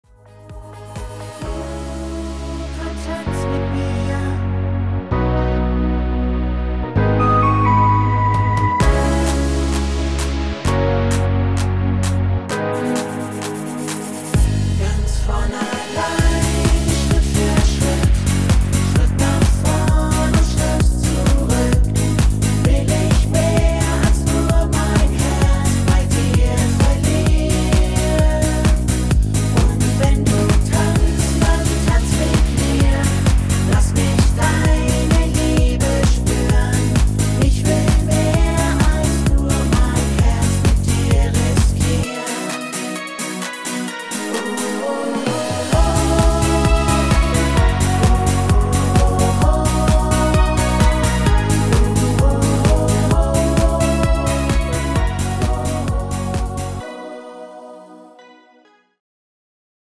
schönes Duett Download Buy
Rhythmus  Discofox